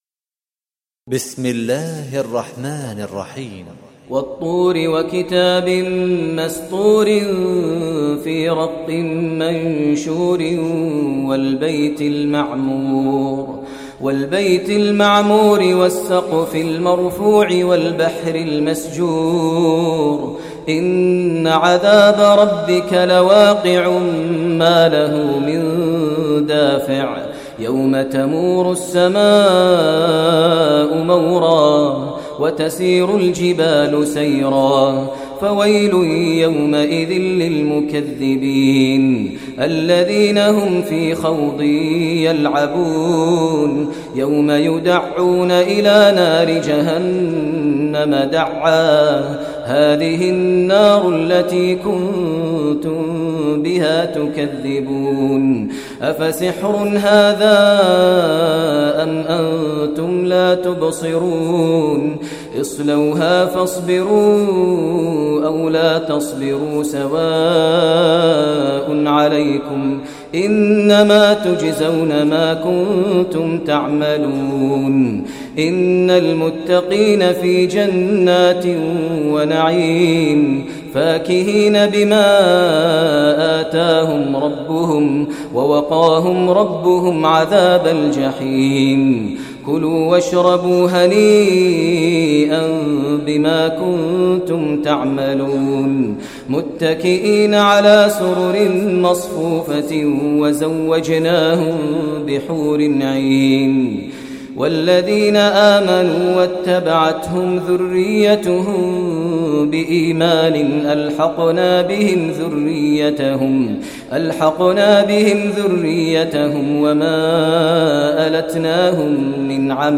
Surah Tur Recitation by Sheikh Maher al Mueaqly
Surah Tur, listen online mp3 tilawat / recitation in Arabic recited by Imam e Kaaba Sheikh Maher al Mueaqly. Surah Tur is 52 chapter of Holy Quran.